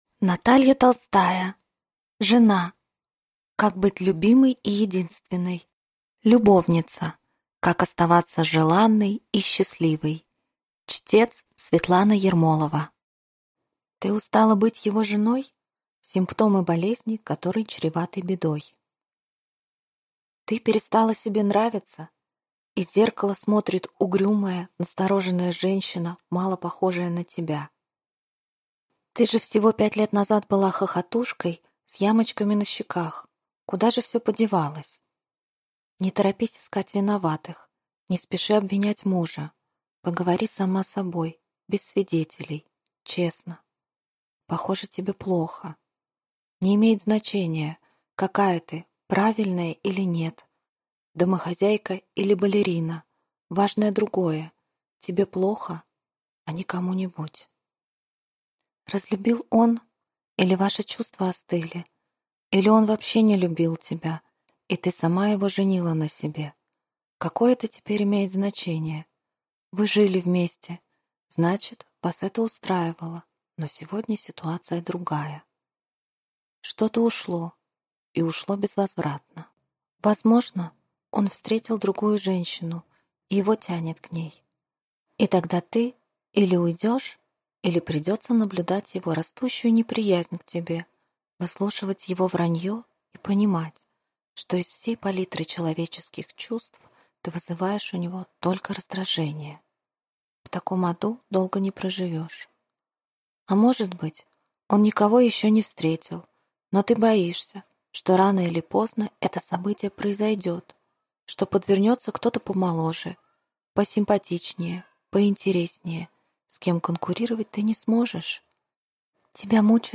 Аудиокнига Жена. Как быть любимой и единственной. Любовница. Как оставаться желанной и счастливой | Библиотека аудиокниг